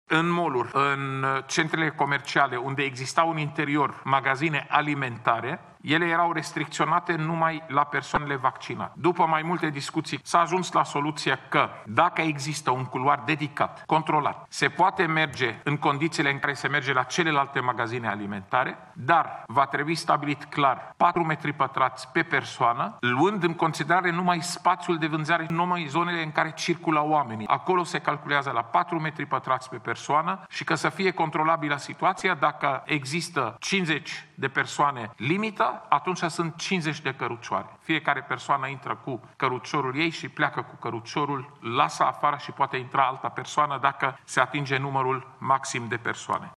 Cu detalii şeful Departamentului pentr Situaţii de Urgenţă, Raed Arafat: